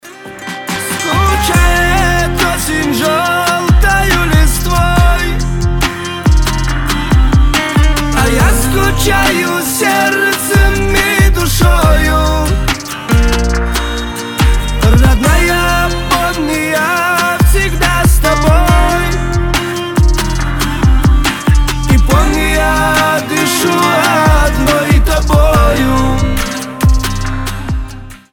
• Качество: 320, Stereo
медленные